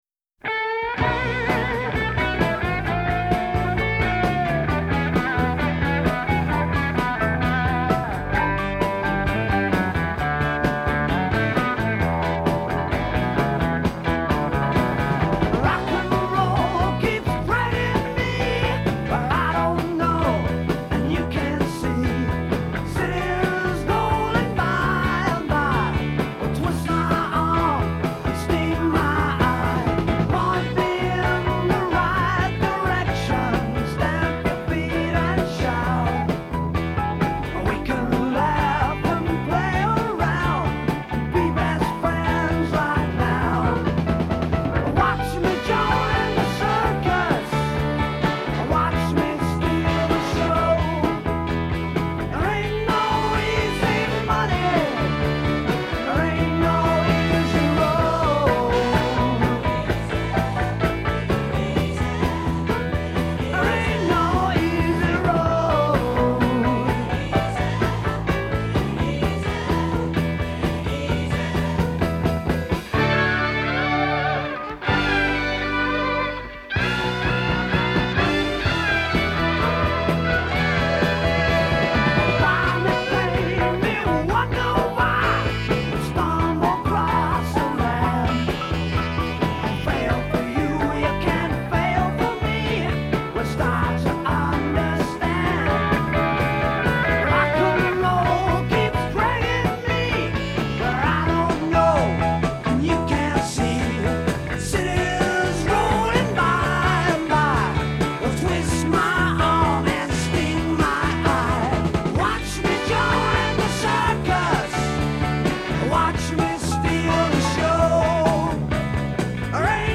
Country: Rock, Hard Rock, Blues Rock